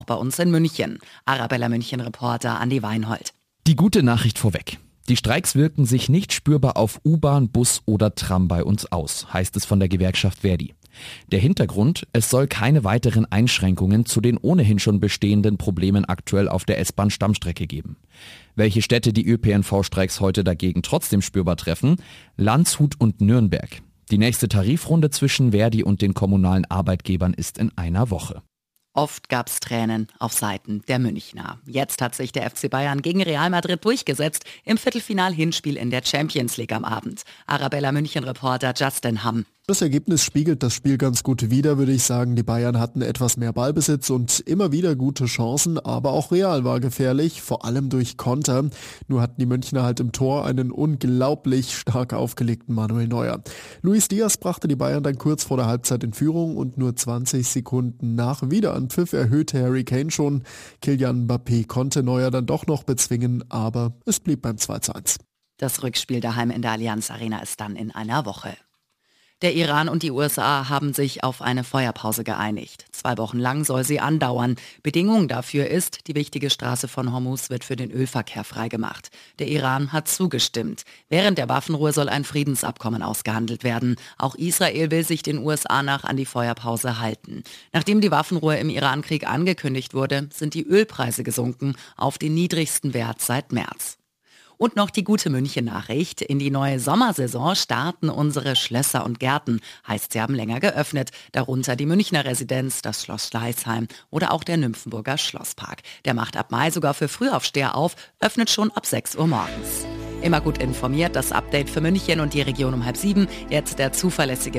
Nachrichten , Nachrichten & Politik
Die aktuellen Nachrichten zum Nachhören